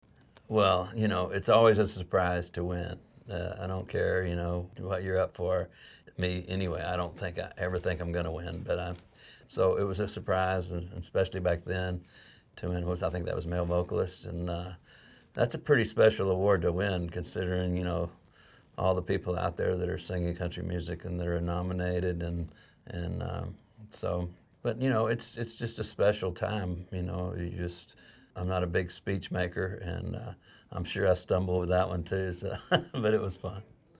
Audio / George Strait talks about winning the CMA Male Vocalist Award for the first time in 1985.
George-Strait-CMA-Male-Vocalist-1985.mp3